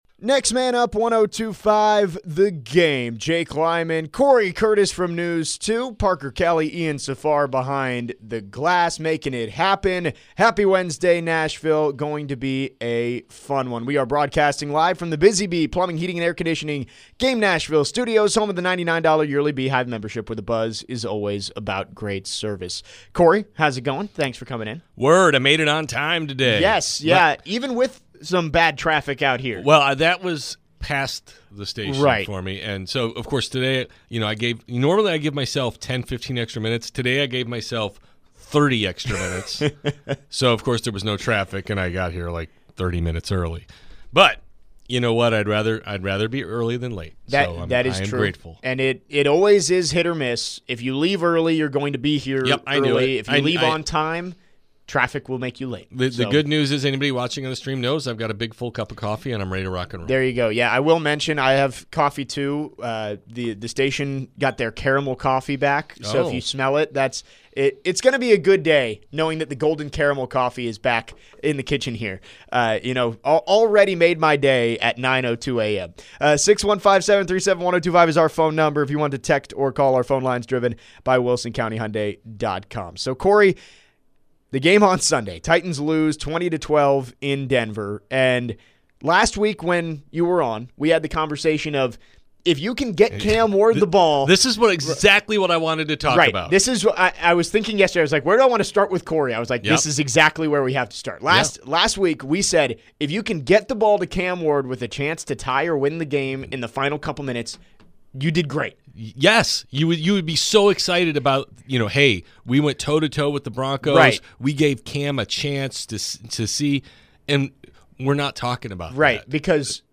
in studio today. They break down what they saw from Titans quarterback Cam Ward in his regular season debut against the Denver Broncos.